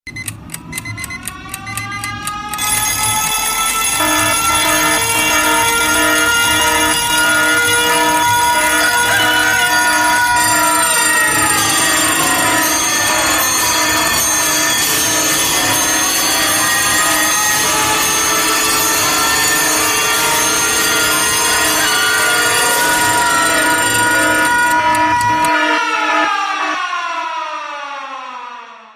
Другие рингтоны по запросу: | Теги: Alarm Clock, будильник